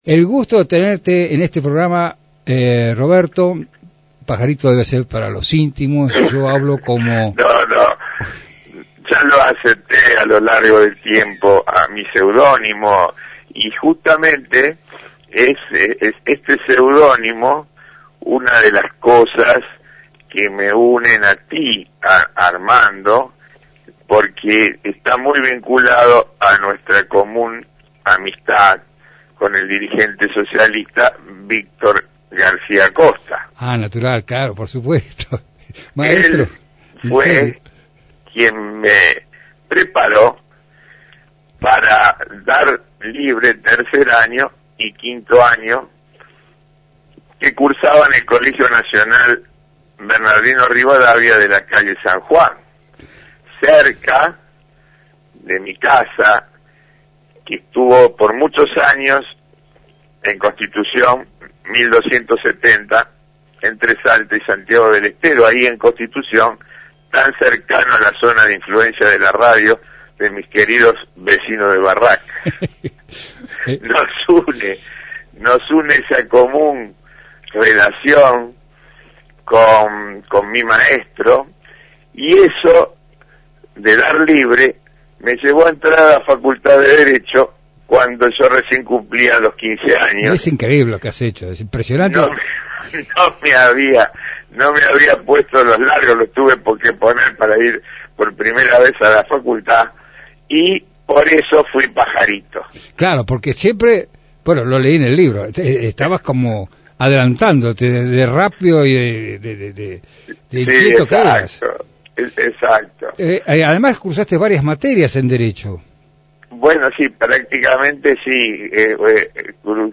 Esos son algunos de sus fragmentos de la entrevista.